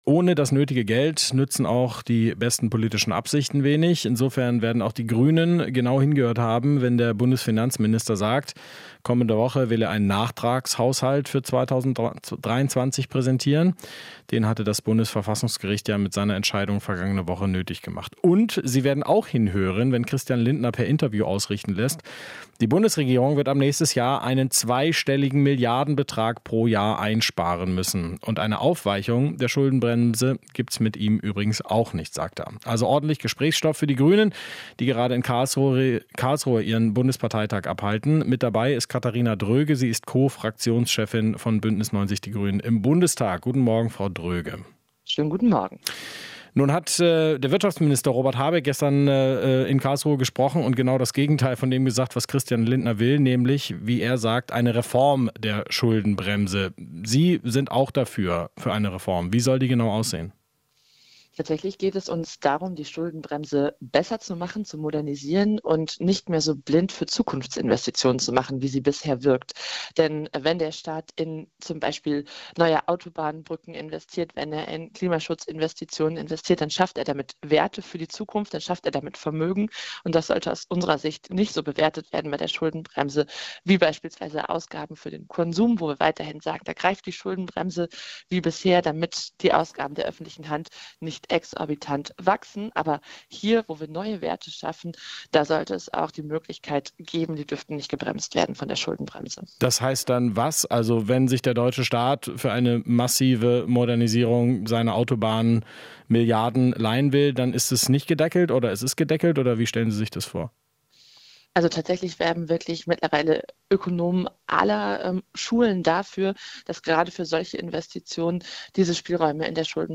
Interview - Dröge (Grüne): Müssen Schuldenbremse modernisieren